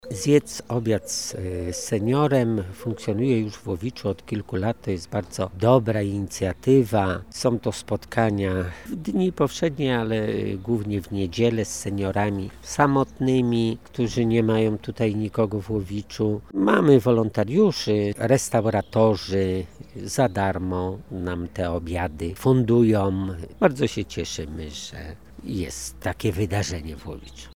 Mówi burmistrz Łowicza Krzysztof Kaliński.